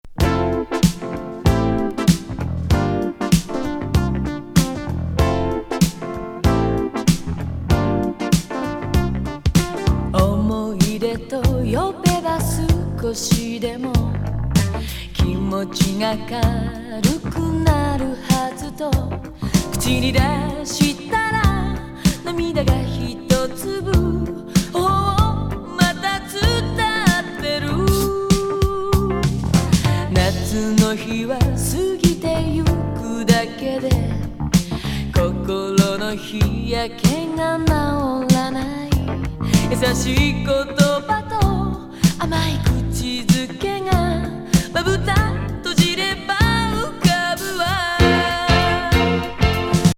シンセ・ライト・メロウな隠れAORシティ・グルーヴィン